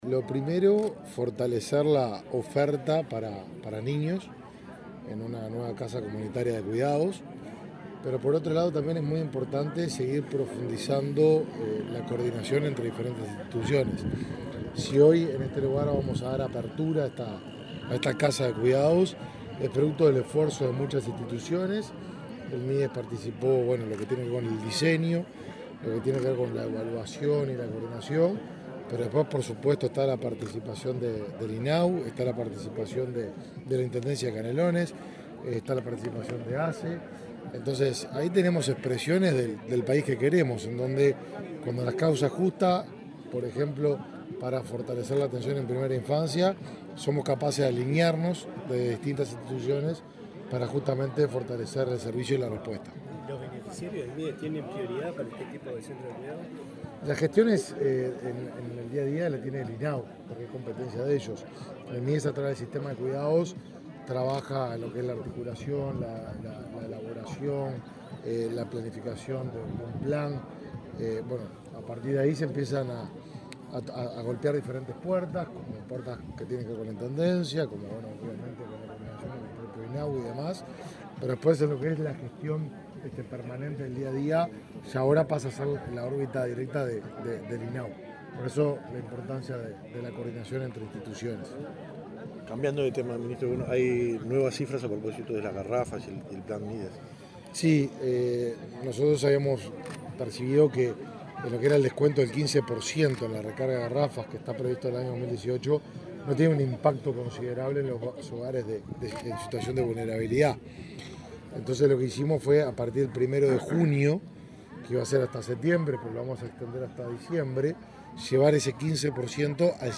Declaraciones a la prensa del ministro de Desarrollo Social, Martín Lema
Declaraciones a la prensa del ministro de Desarrollo Social, Martín Lema 07/09/2022 Compartir Facebook X Copiar enlace WhatsApp LinkedIn El Sistema de Cuidados del MIDES y el INAU inauguraron la casa comunitaria de cuidados "Gotitas de Agua" en Aguas Corrientes, departamento de Canelones. Luego, el ministro Martín Lema dialogó con la prensa.